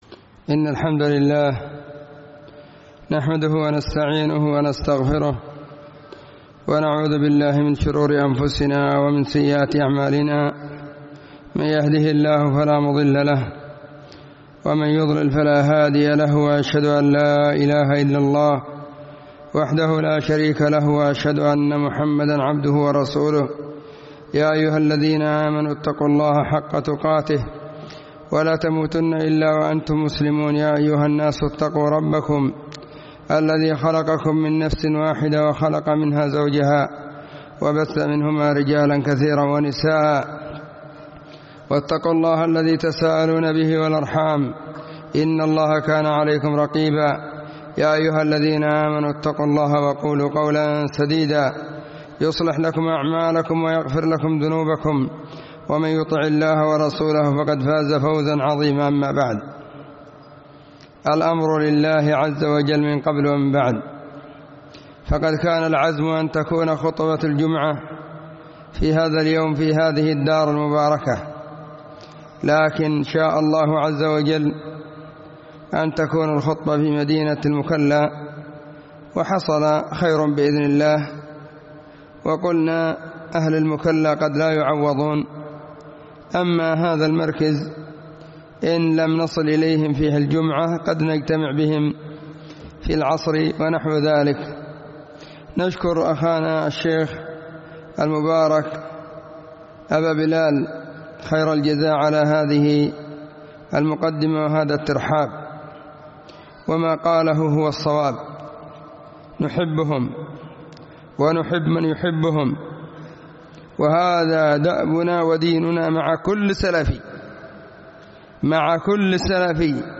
الهداية إلى طريق الولاية كلمة في الحامي بعد العصر /20 جمادى الآخرة 1441 هـ